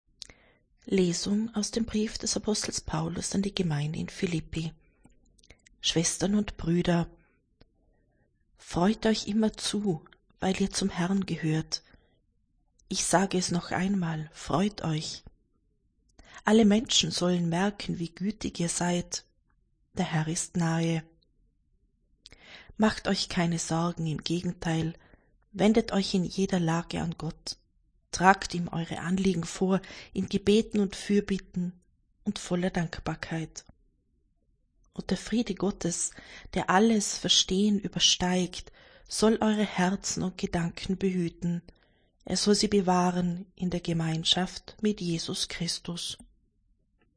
Wenn Sie den Text der 2. Lesung aus dem Brief des Apostels Paulus an die Gemeinde in Philíppi anhören möchten: